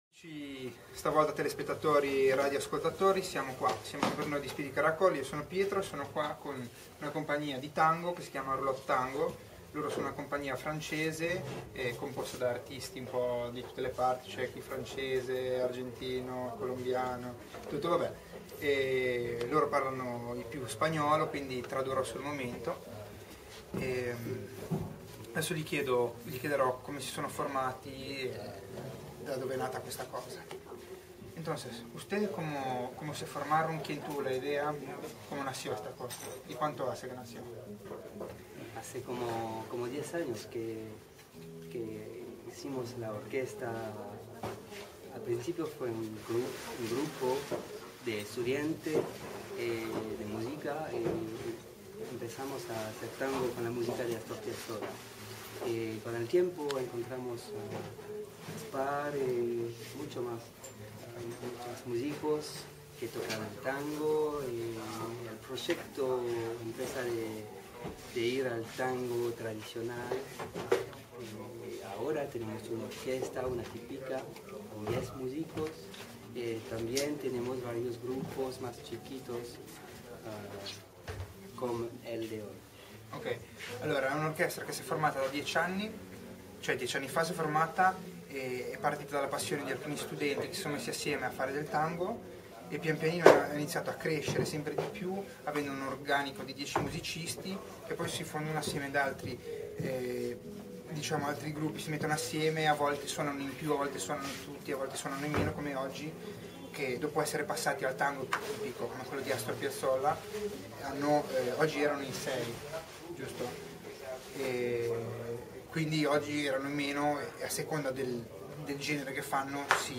Intervista a Roulette Tango Band